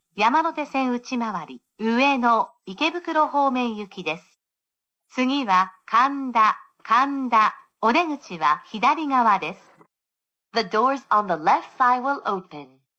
プロフェッショナルな列車アナウンス音声AI
テキスト読み上げ
明瞭な発音
当社のAIは、駅名や安全指示を明確に発音するプロフェッショナルで信頼性のあるトーンを提供し、乗客体験を向上させます。